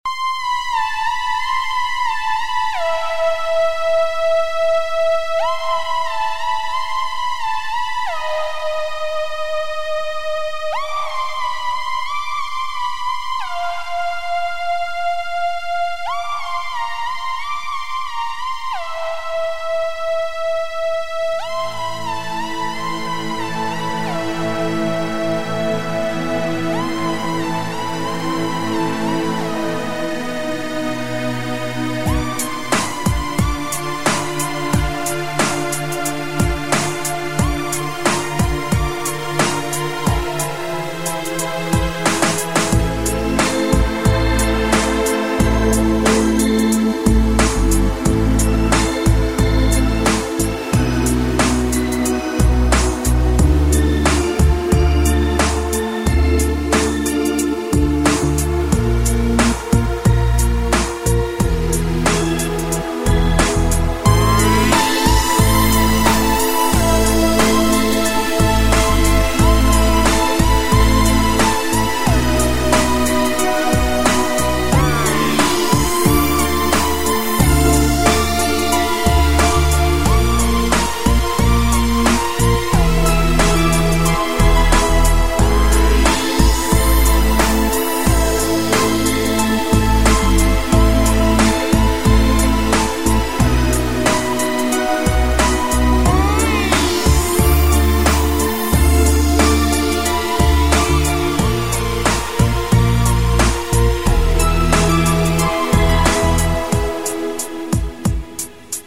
Саундтрек к мультфильму
Тема финальных титров Ура!